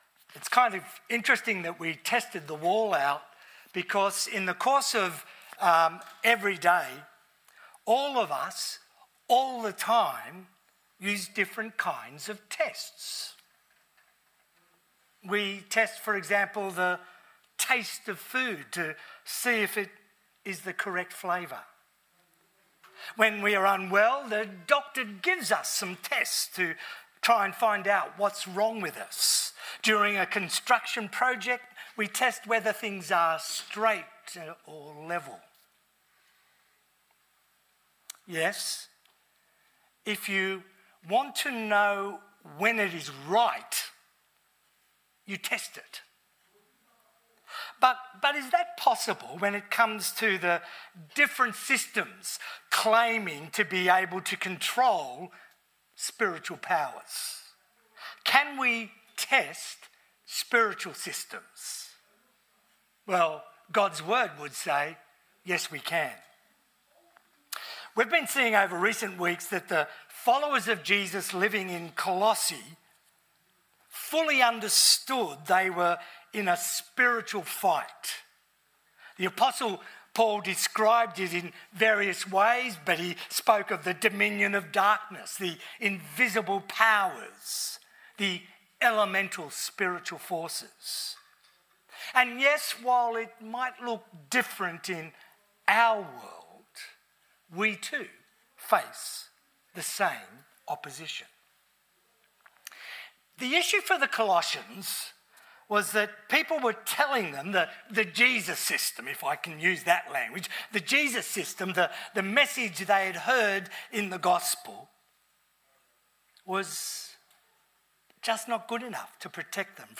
Categories Sermon Leave a Reply Cancel reply Your email address will not be published.